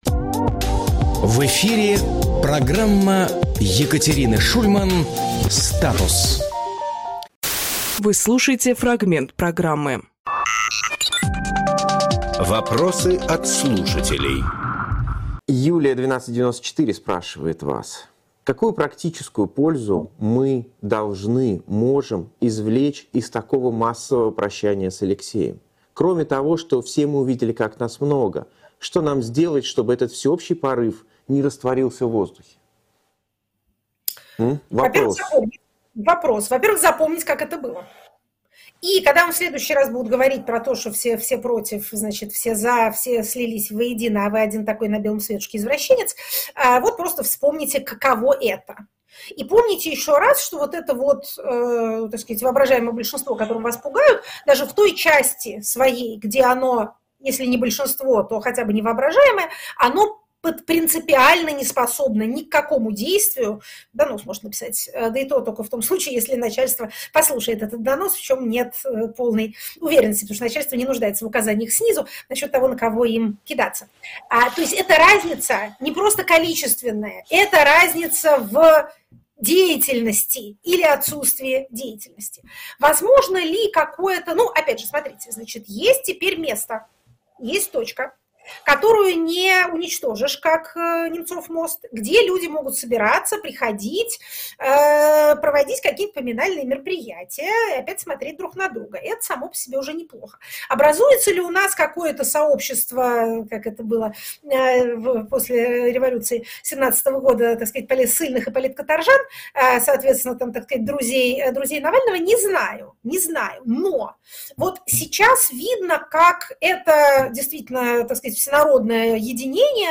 Екатерина Шульманполитолог
Фрагмент эфира от 05.03.24